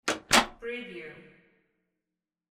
Door close sound effect .wav #15
Description: Door snaps close
Properties: 48.000 kHz 24-bit Stereo
Keywords: door, close, closing, pull, pulling, push, pushing, shut, shutting, house, apartment, office, room
door-15-close-preview-1.mp3